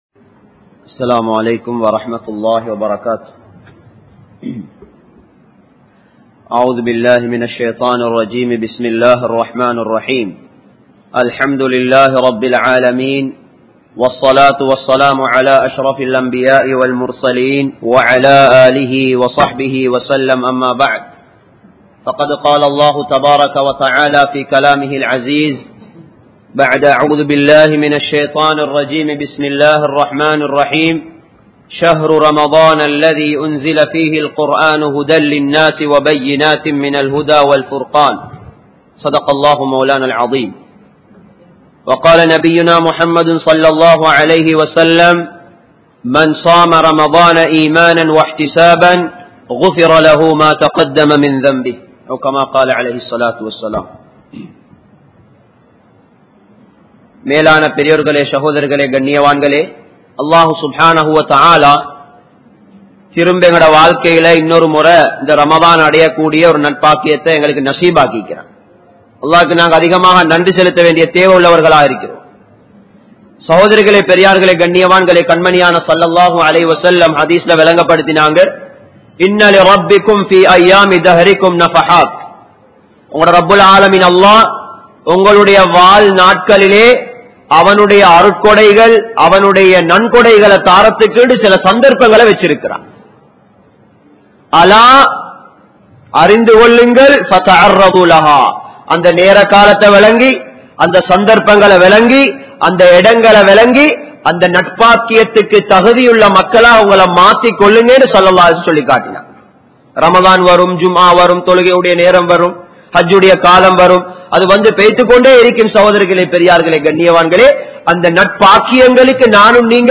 Paakkiyam Pettrum Vilakkam Illaathavarhal (பாக்கியம் பெற்றும் விளக்கம் இல்லாதவர்கள்) | Audio Bayans | All Ceylon Muslim Youth Community | Addalaichenai